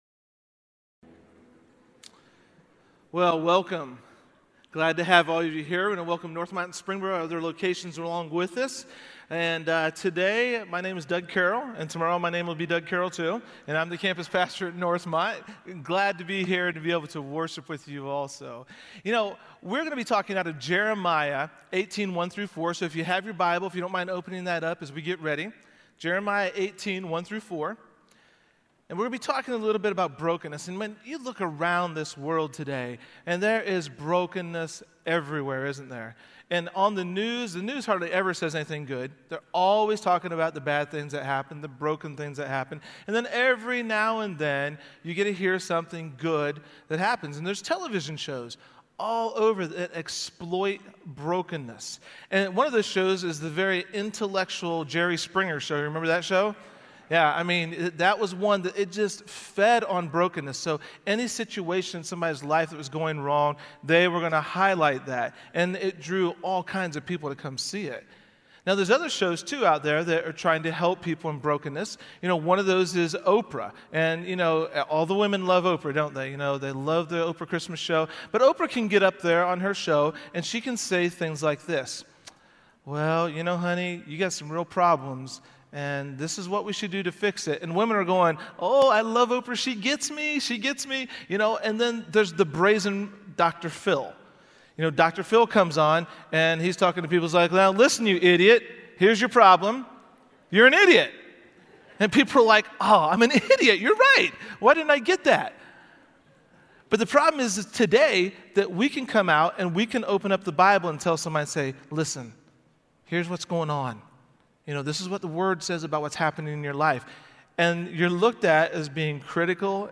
Check out Broken, a sermon series at Fairhaven Church.